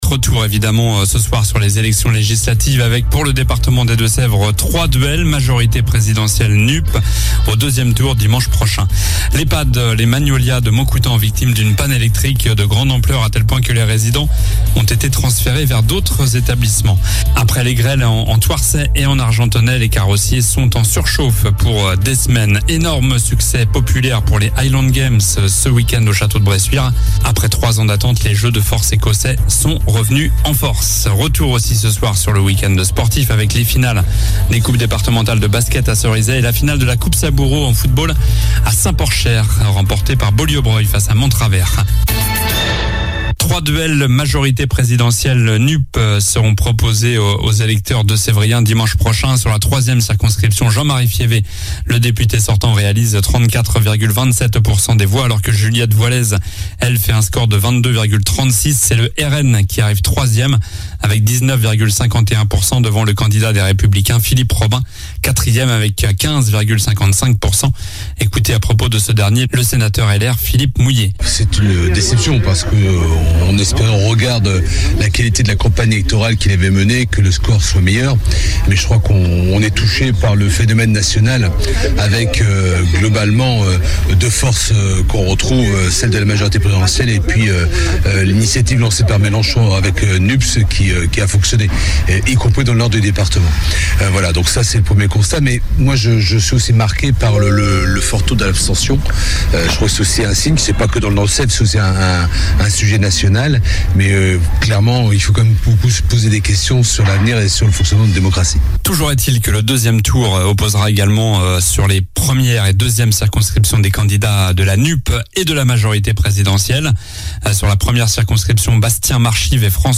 JOURNAL DU LUNDI 13 JUIN ( SOIR )